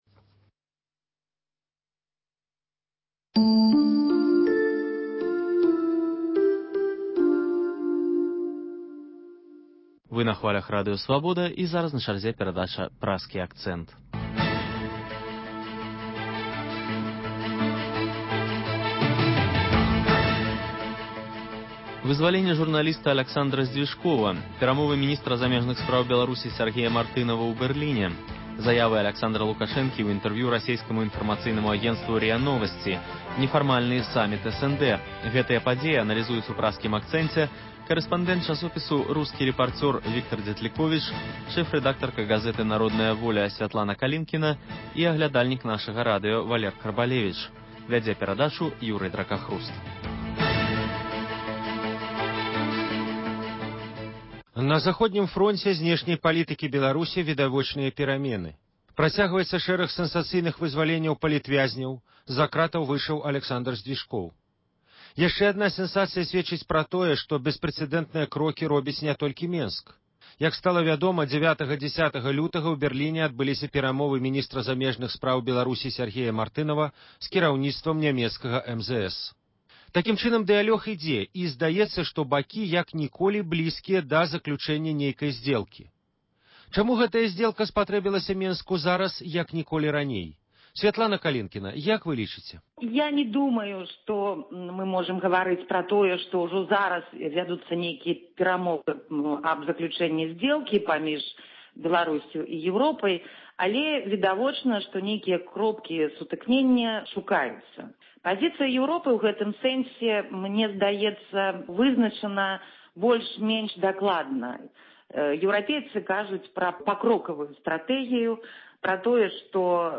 Круглы стол крытыкаў, прысьвечаны 115-годзьдзю з дня нараджэньня Максіма Гарэцкага.